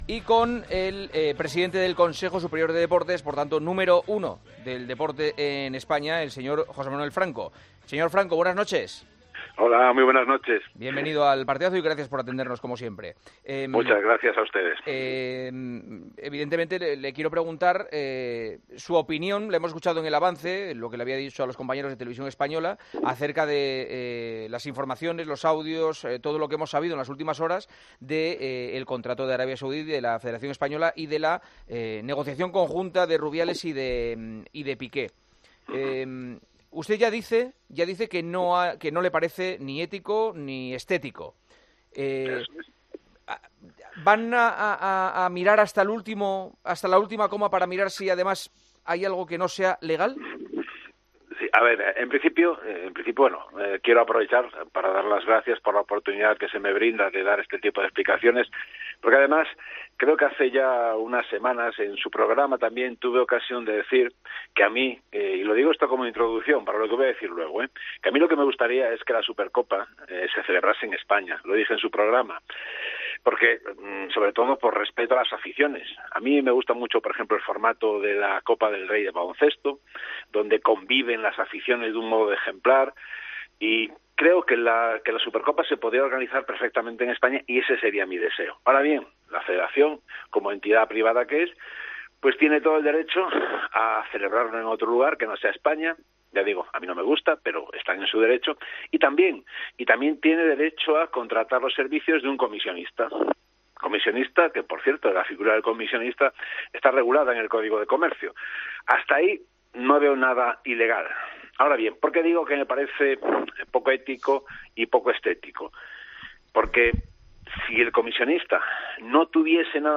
AUDIO - ENTREVISTA A JOSÉ MANUEL FRANCO, EN EL PARTIDAZO DE COPE